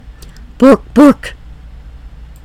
GROWLITHE.mp3